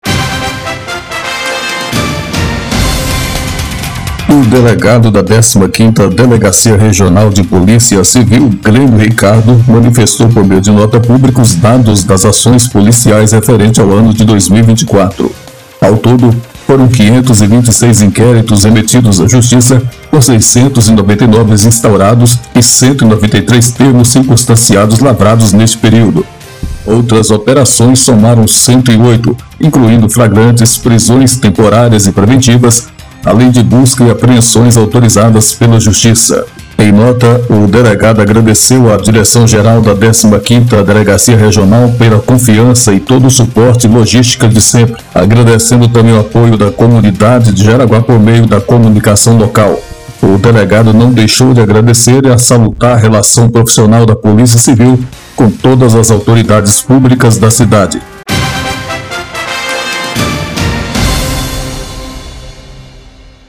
VINHETA-POLICIA2.mp3